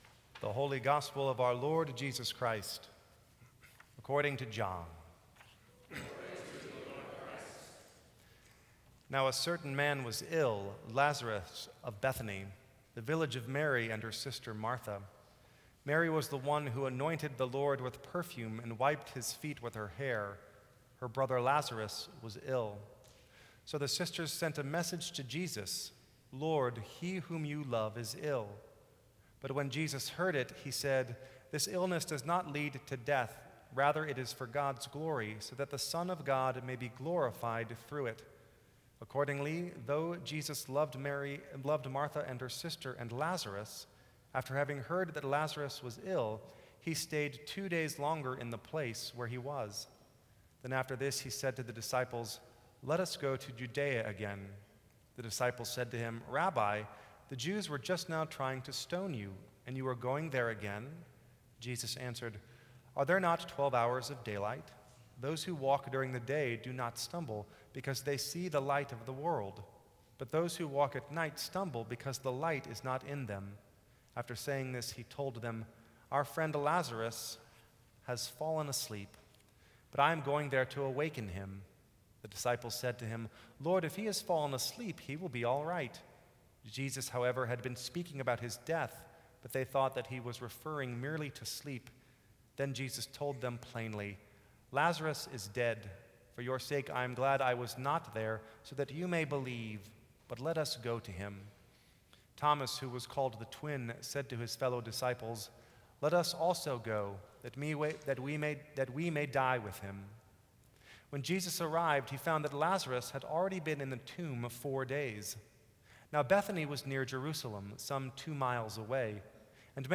Sermons from St. Cross Episcopal Church April 6, 2014.